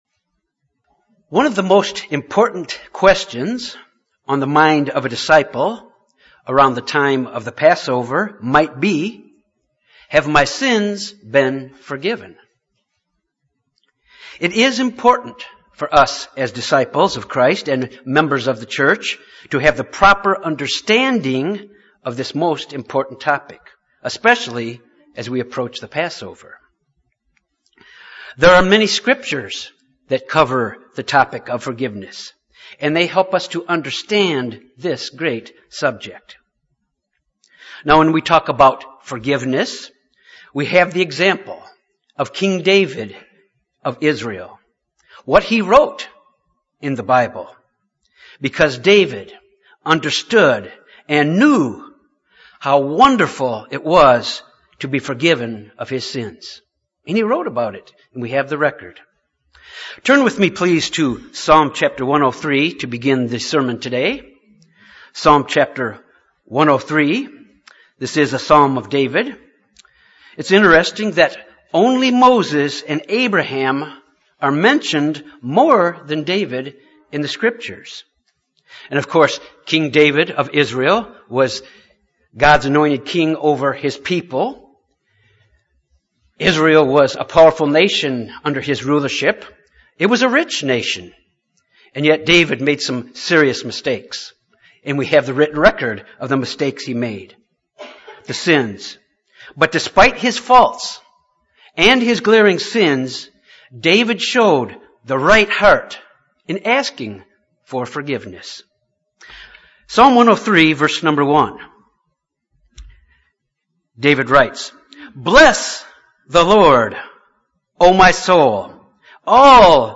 This sermon deals with how sins are forgiven. There is a process and the process must take place.